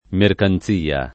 [ merkan Z& a ]